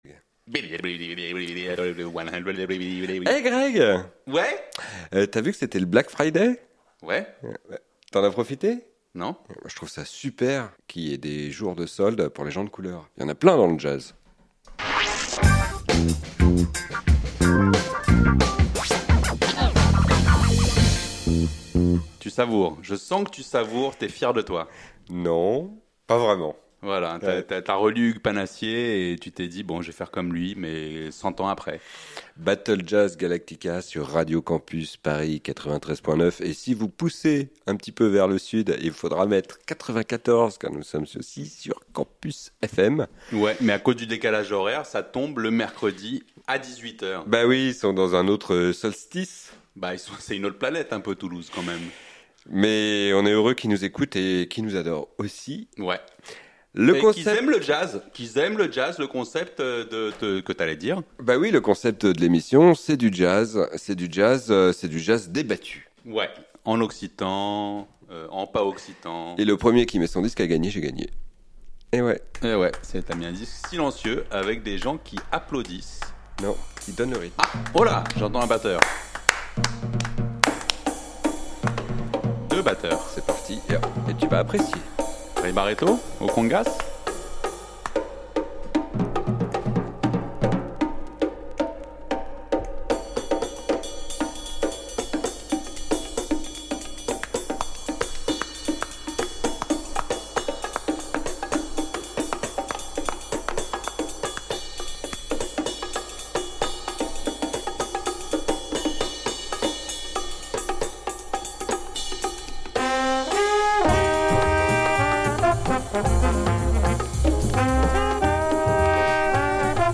Pendant 1 heure, l'un n'épargne pas l'autre. Tu aimes le funk ? Ah zut, je te mets du Bop.
Flûte, je te mets une fille à la voix sublime qui chante à contre-temps...